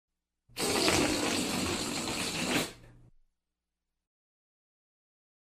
Big Wet Fart Sound Button - Free Download & Play